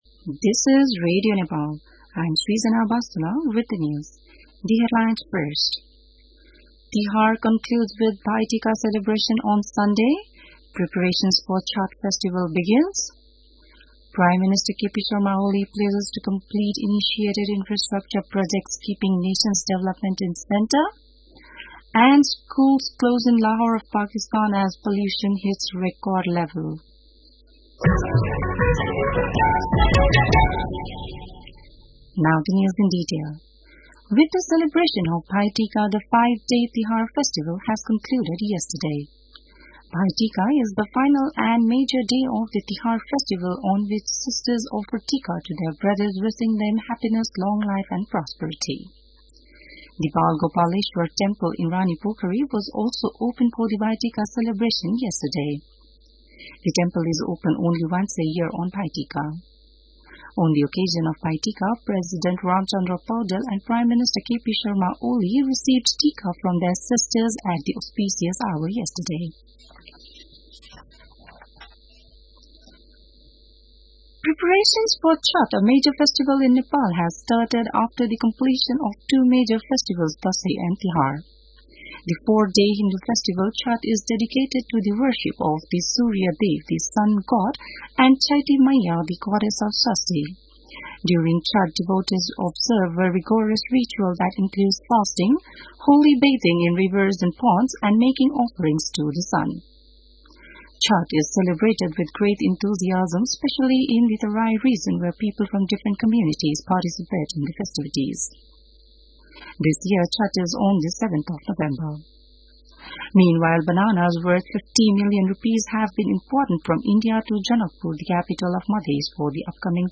बिहान ८ बजेको अङ्ग्रेजी समाचार : २० कार्तिक , २०८१